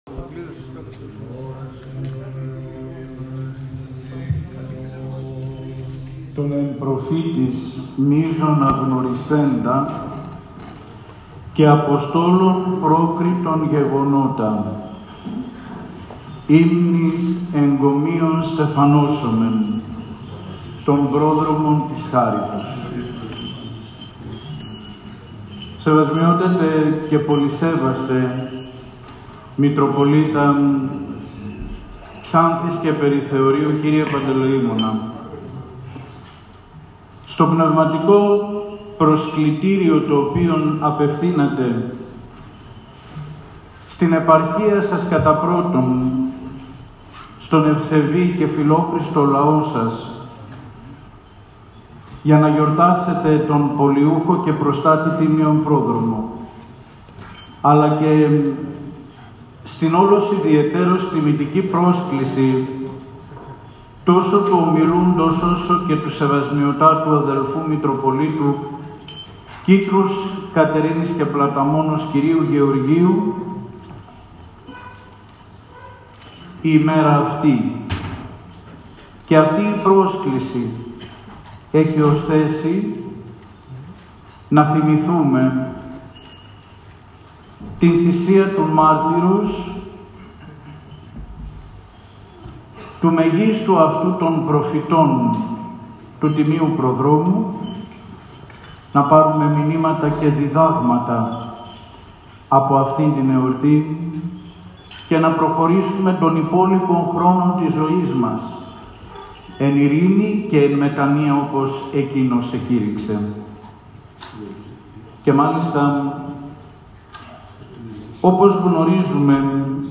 Στον Ιερό Μητροπολιτικό Ναό Τιμίου Προδρόμου Ξάνθης έλαβε χώρα η ακολουθία του Πανηγυρικού Όρθρου καθώς και Πολυαρχιερατικό Συλλείτουργο επι τη εορτή της Αποτομής της Κάρας του Τιμίου Προφήτου Προδρόμου και Βαπτιστού Ιωάννου.
Προεξάρχων της Πολυαρχιερατικής Θείας Λειτουργίας ήταν ο Σεβασμιώτατος Μητροπολίτης Ξάνθης και Περιθεωρίου κ. Παντελεήμων , με τον οποίο συνιερούργησαν ο Σεβασμιώτατος Μητροπολίτης Μαρωνείας και Κομοτηνής κ. Παντελεήμων , ο οποίος και κήρυξε τον Θείο Λόγο αναφερόμενος στο μαρτύριο και την μαρτυρία του Τιμίου Προδρόμου και ο Σεβασμιώτατος Μητροπολίτης Κίτρους Κατερίνης και Πλαταμώνος κ. Γεώργιος, περιστοιχιζόμενοι από ιερείς της Μητροπόλεως Ξάνθης.